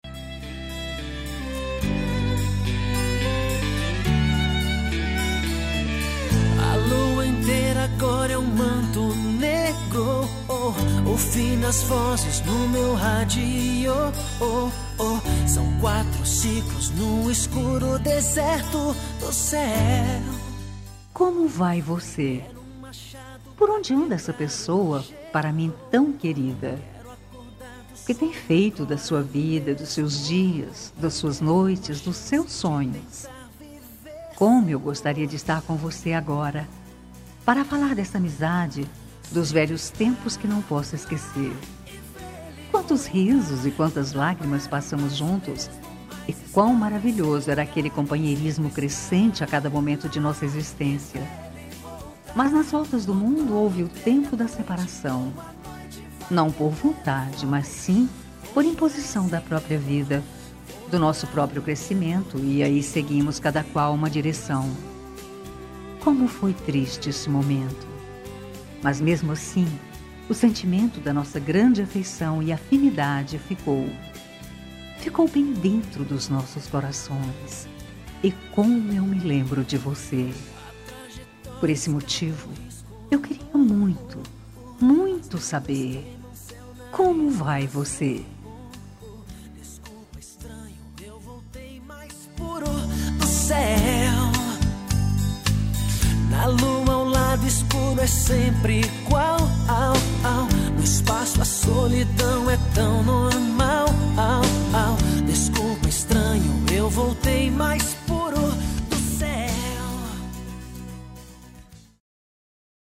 Telemensagem Amizade – Voz Feminina – Cód: 29321 – Distante
29321-amizade-fem-distante.m4a